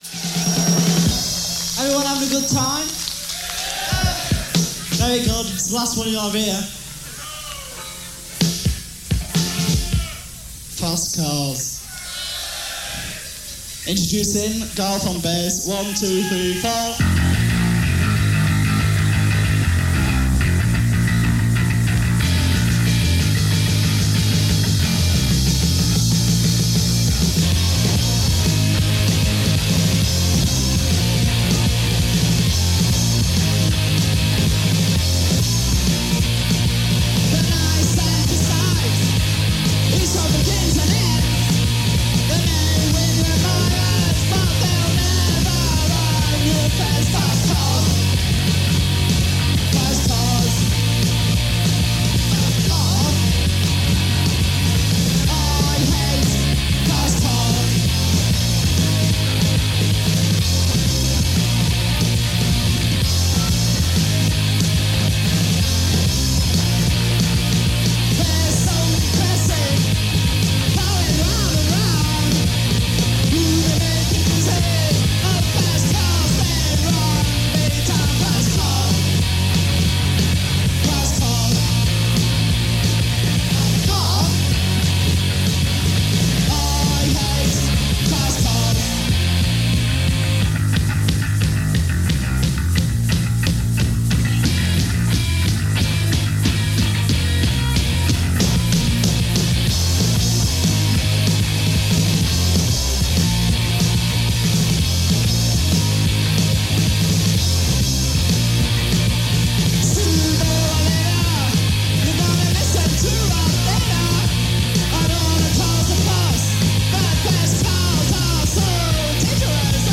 So it’s not only a high-voltage show, it’s a historic one.